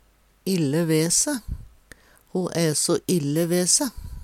ille ve se - Numedalsmål (en-US)